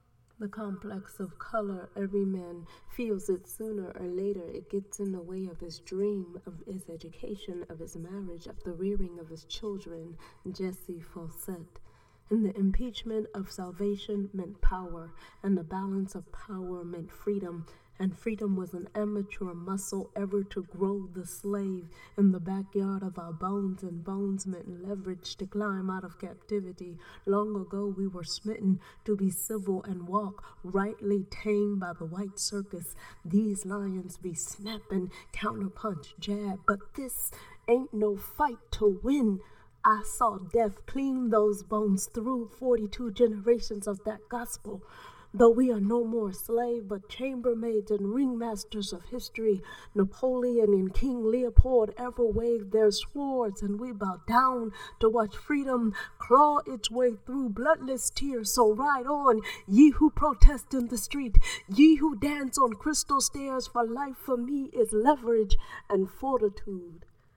What a compelling, passionate voice you have!